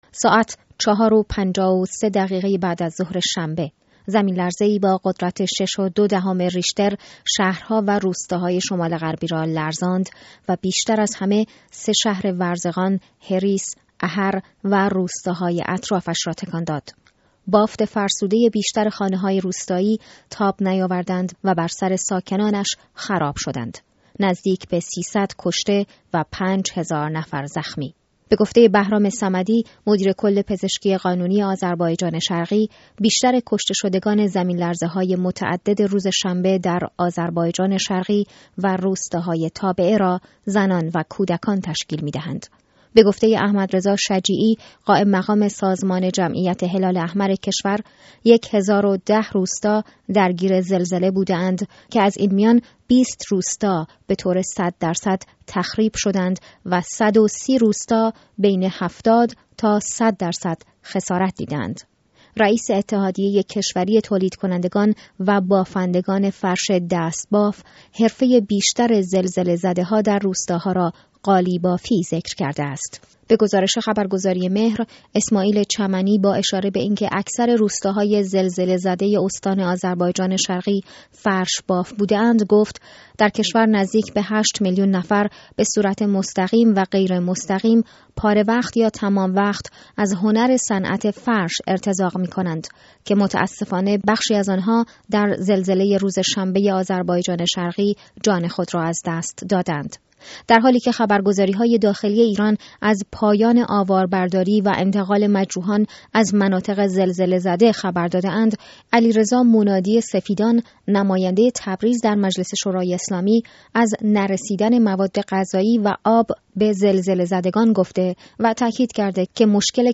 گفت‌وگو با یک خبرنگار در محل